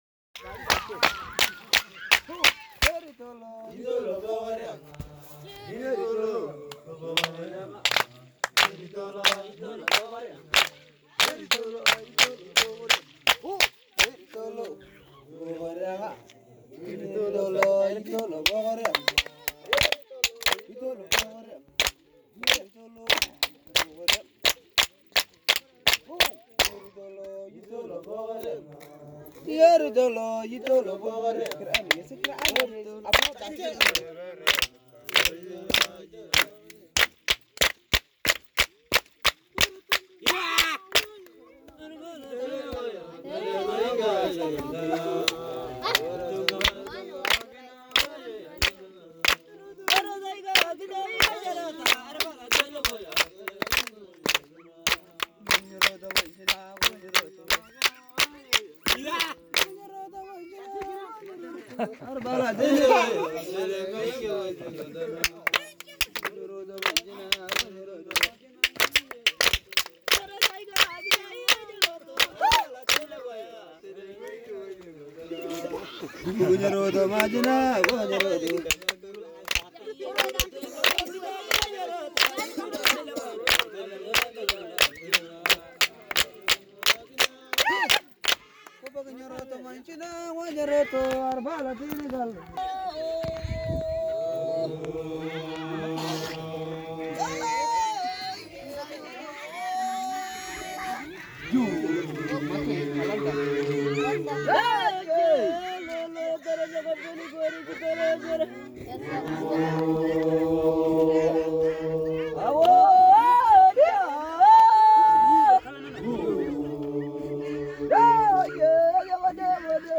Canto hamer hombres
Canto-hamer-hombres-2.m4a